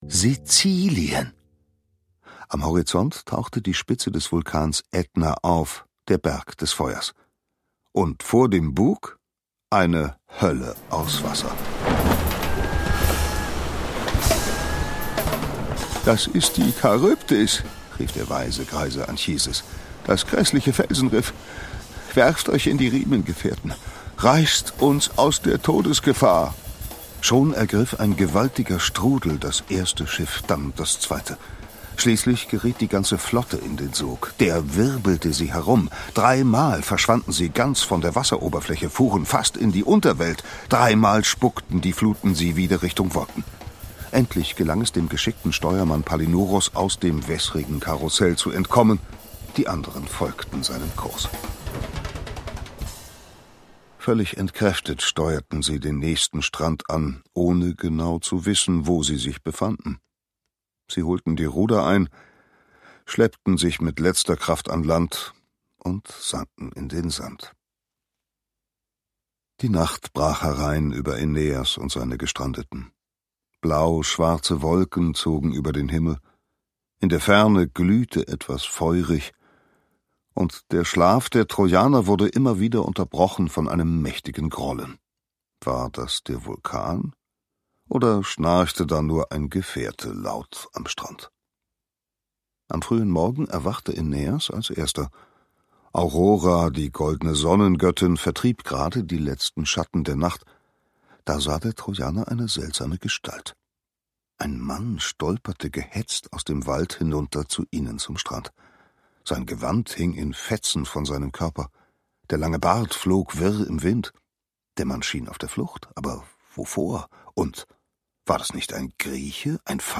Hörbuch: Aeneas.